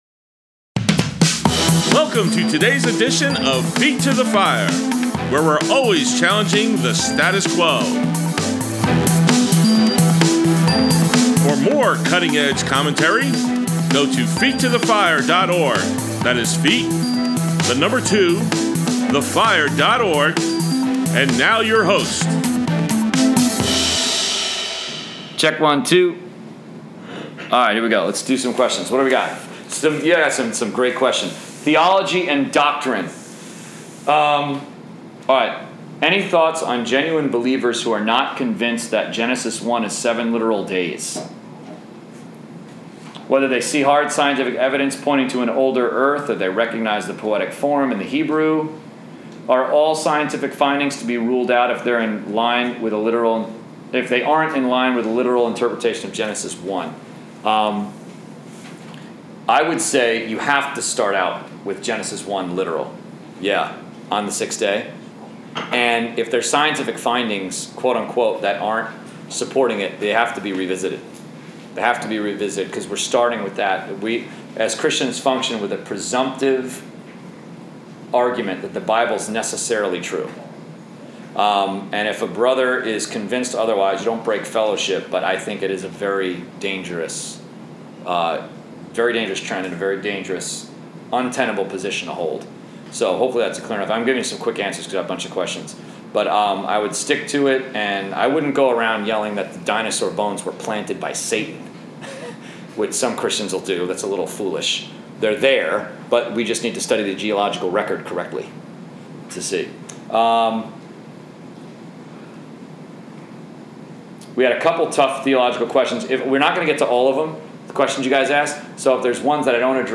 Men’s Study, Abuse of Liberty Part 4: Q&A With A Pastor And His Wife